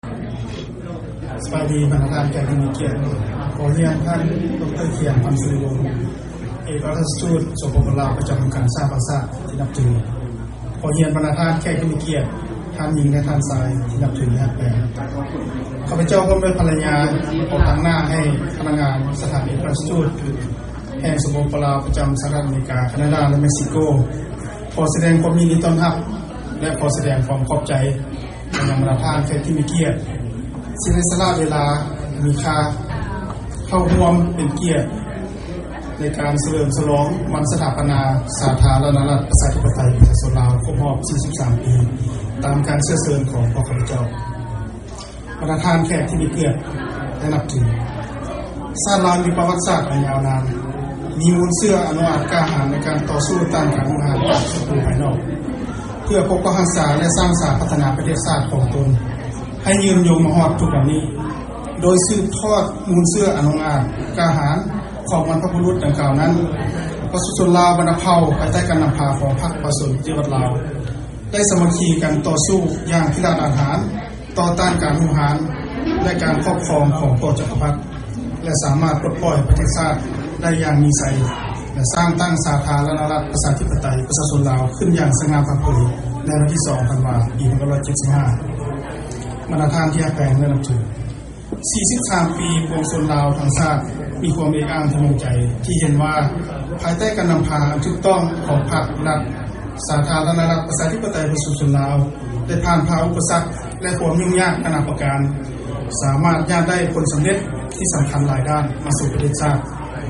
ເຊີນຟັງການກ່າວຄຳປາໄສ ກ່ຽວກັບ ວັນຊາດ ທີ 2 ທັນວາ ຂອງ ທ່ານ ໄມ ໄຊຍະວົງ ເອກອັກຄະລັດຖະທູດລາວ ປະຈຳ ວໍຊິງຕັນ